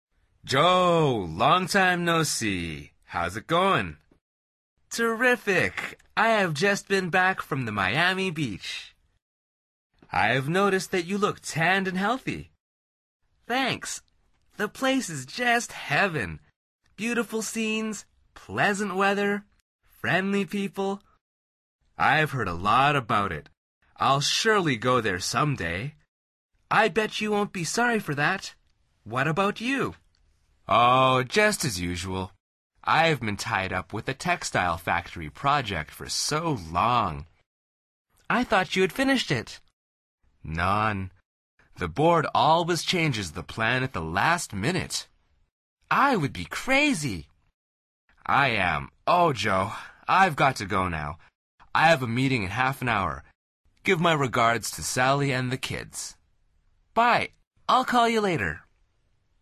Al final repite el diálogo en voz alta tratando de imitar la entonación de los locutores.
dialog2.mp3